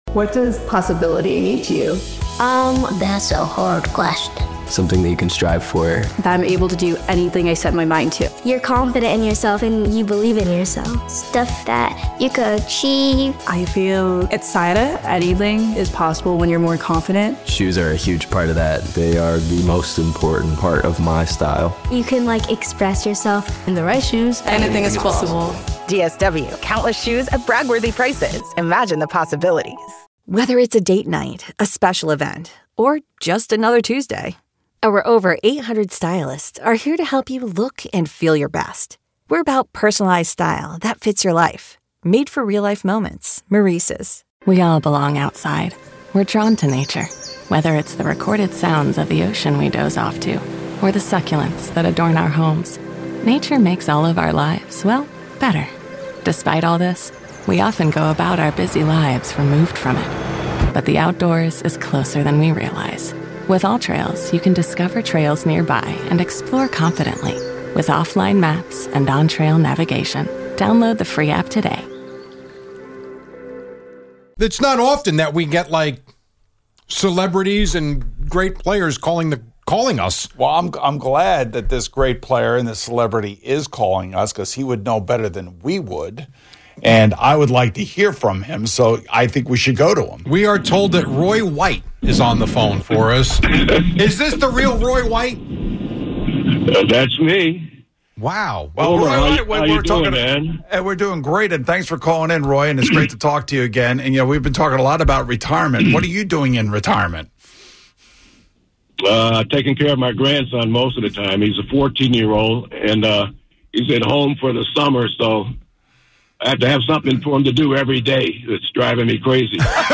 Yankee Legend Roy White Calls In